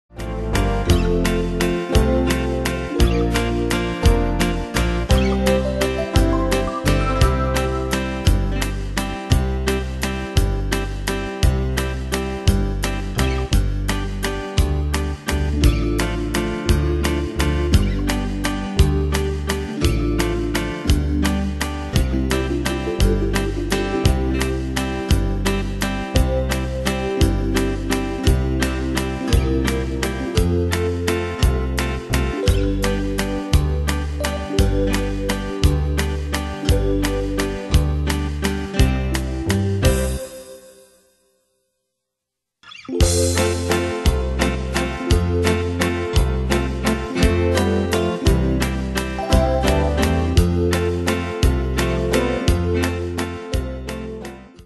Danse/Dance: Valse/Waltz Cat Id.
Pro Backing Tracks